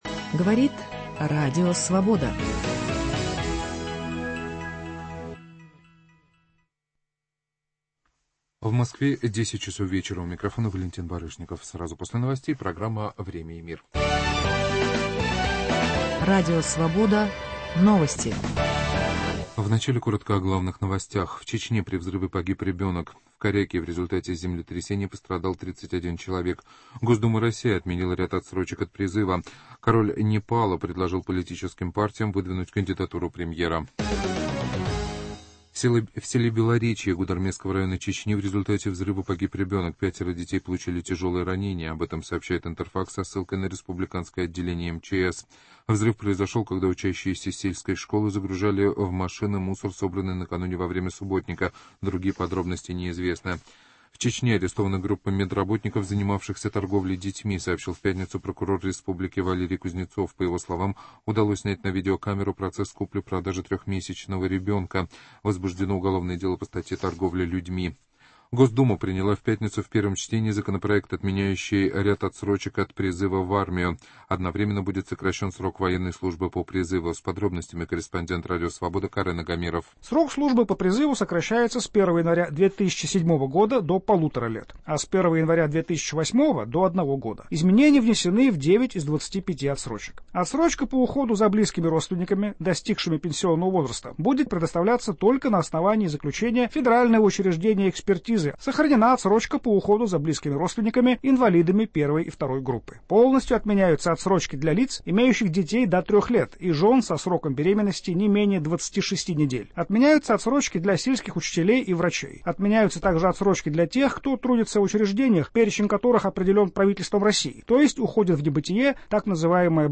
Интервью с сенатором США Джоном Маккейном Новый закон о религии в Сербии Экология: Проект экологического государственного мониторинга Наука: Происхождение млекопитающих